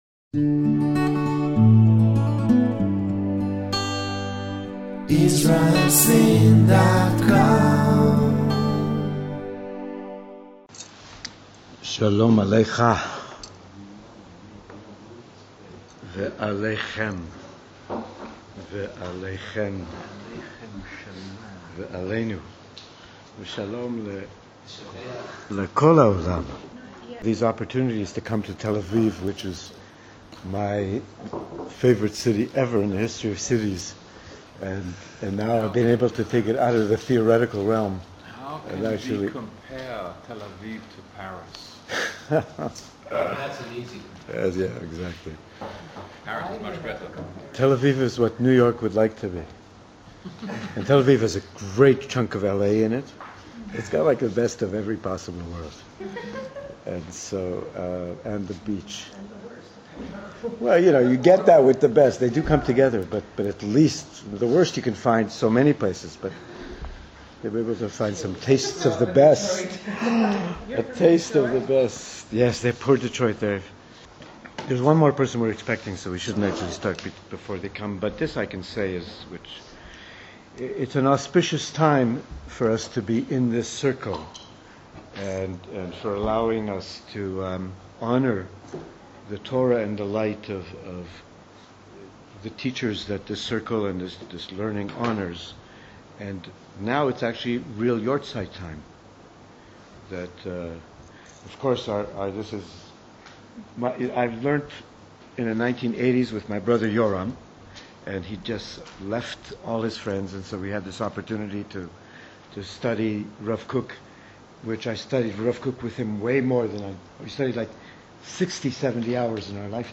Lecturer